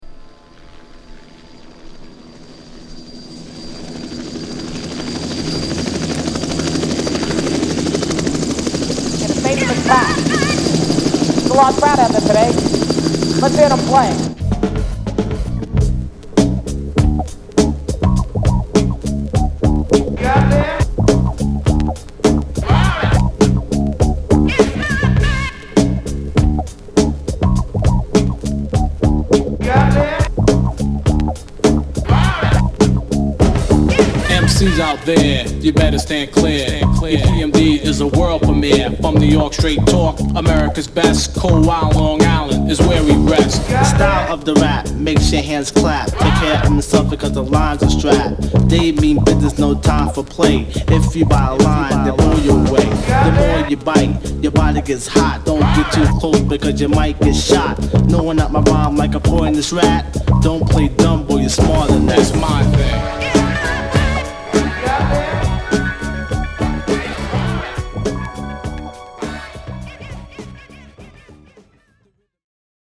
ホーム HIP HOP 80's 12' & LP E